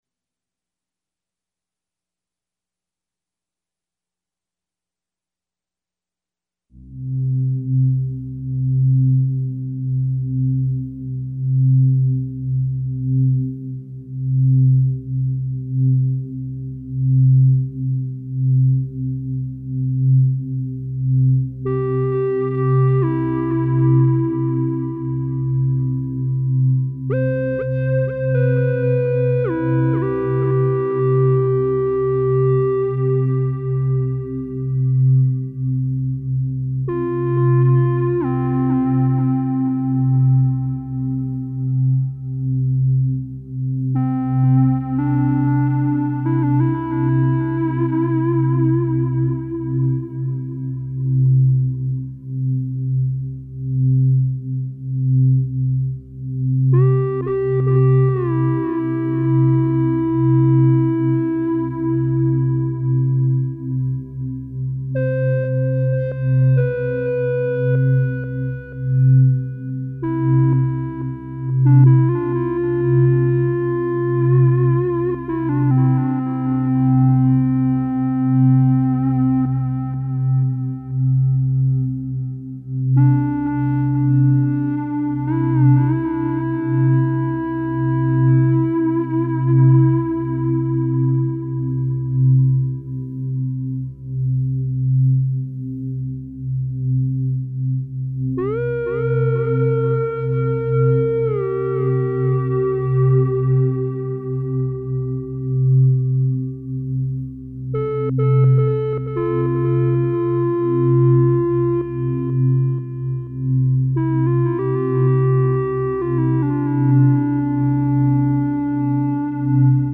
Plus everybody likes parallel 5ths! The human ear likes parallel 5ths because it grounds the music in a sweet spot. So its all release and little tension.